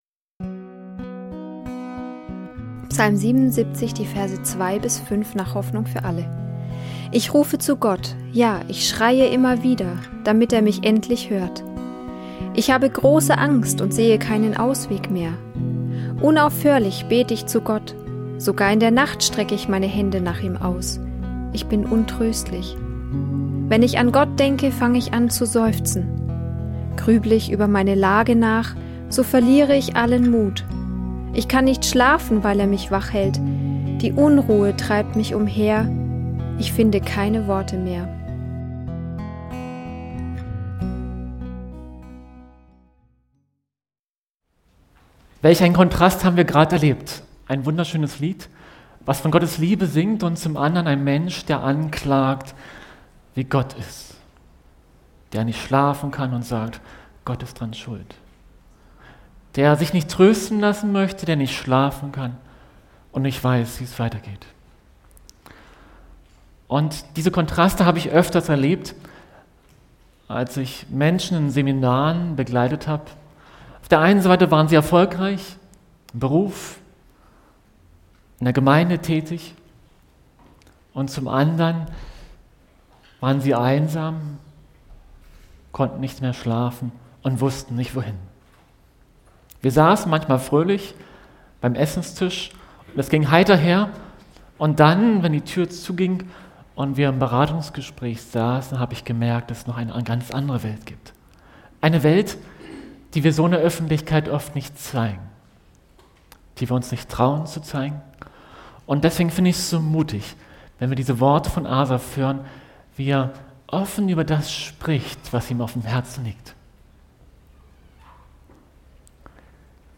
Dieses tiefgehende Seminar